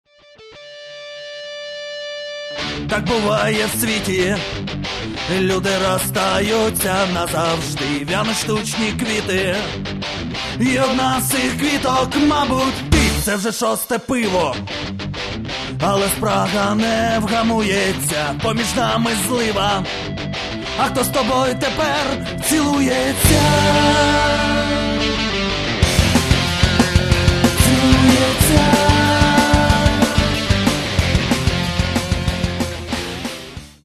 Каталог -> Рок и альтернатива -> Сборники